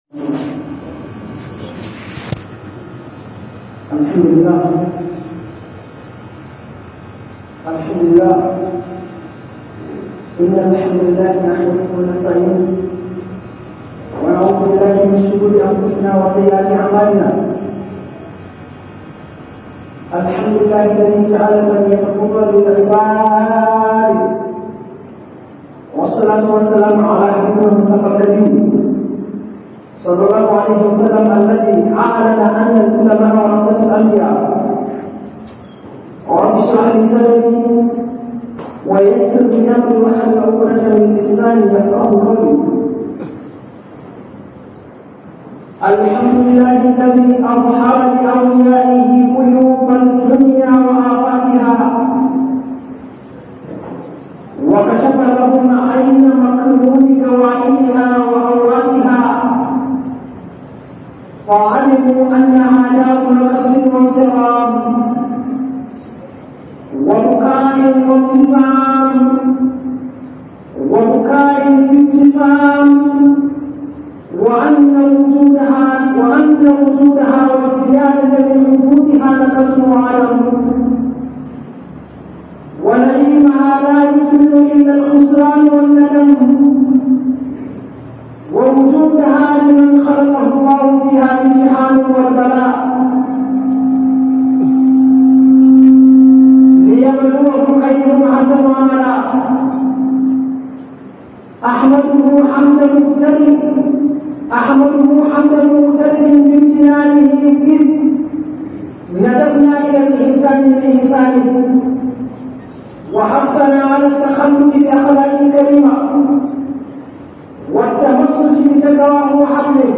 Tanadi Dan kyakykyawan Aiki - HUDUBA